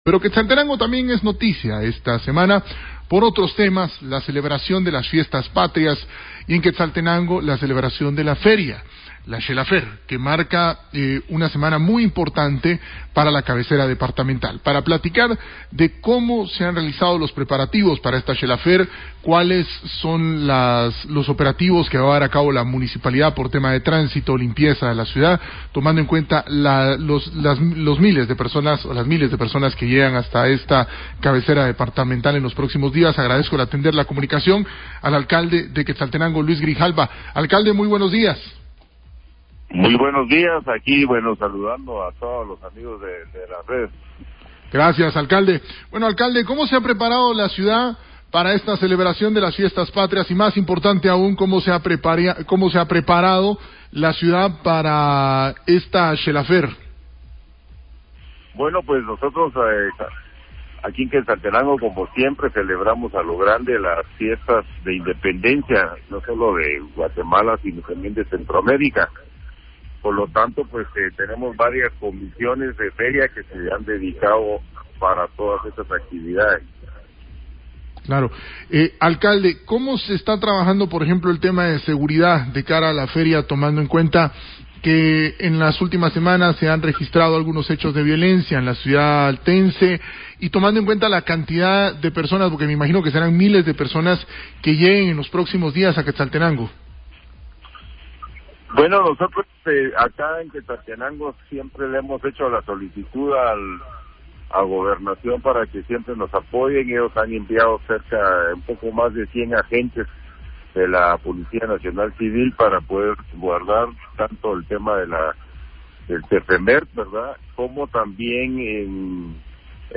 Fuente: RCN NOTICIAS / LA RED: Entrevista con Luis Grijalva, Alcalde de Quetzaltenango. Conversando sobre la celebración de la Feria de Quetzaltenango. Fallas en el fluido eléctrico, subestación del INDE minuto 4:04.
Conversando-con-el-alcalde-de-Xela.mp3